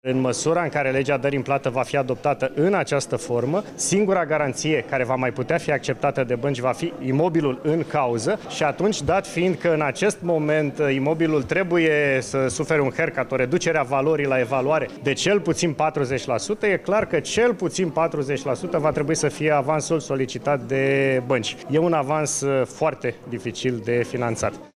Pe de altă parte, viceguvernatorul BNR Bogdan Olteanu a lăsat să se înțeleagă că, dacă legea trece în forma actuală, băncile ar fi îndreptățite să crească avansul la creditele ipotecare: